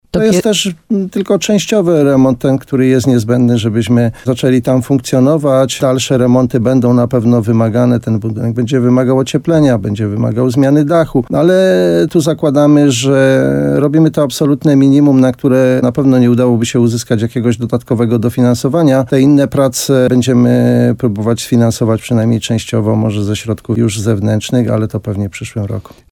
– Pomieszczenia muszą być też przekształcone z sal lekcyjnych na pokoje biurowe – mówił wójt gminy Szczawa Janusz Opyd w programie Słowo za Słowo na antenie RDN Nowy Sącz.